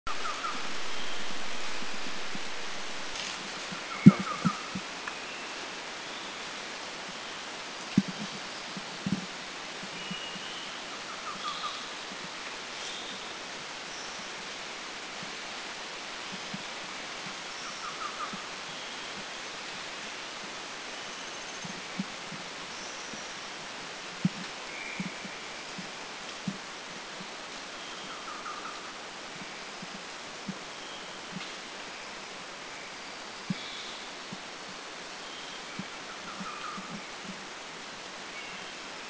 Orange-breasted Trogon
Orange-breastedTrogon.mp3